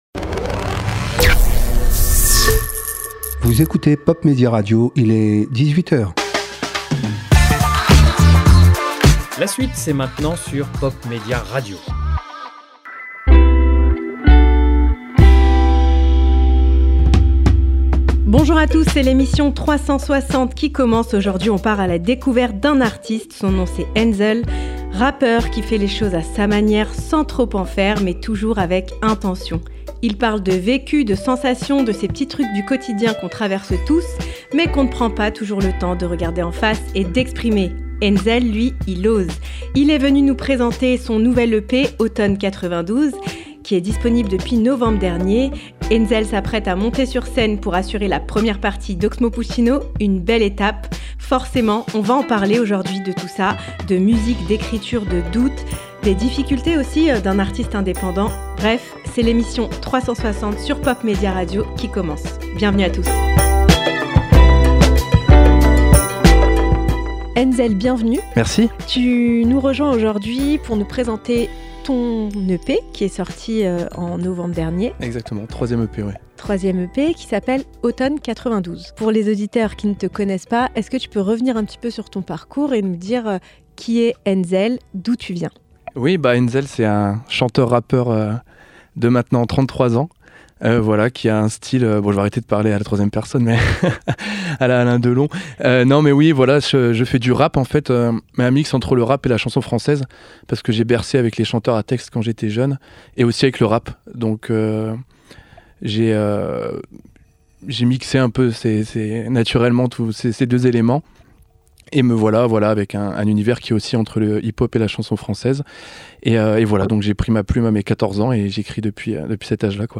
Une discussion sans filtre. Plongez dans son univers, sa vision, son énergie…